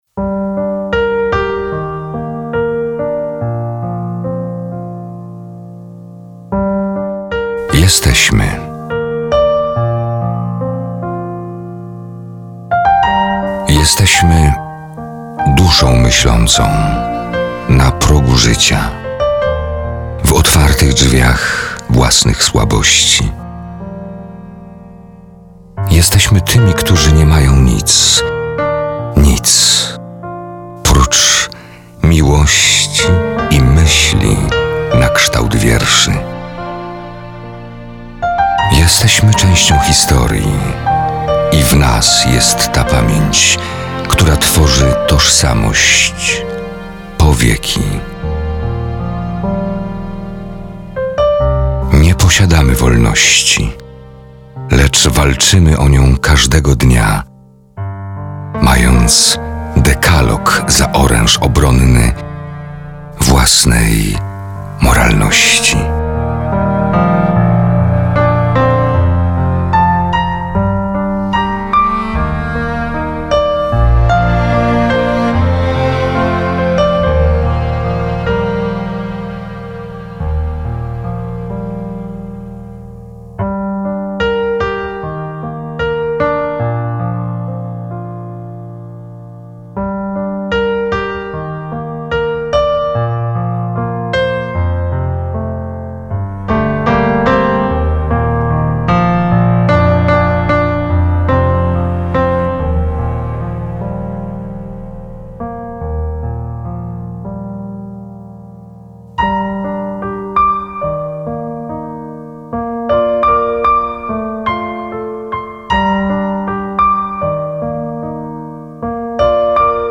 Male 50 lat +
Nagranie lektorskie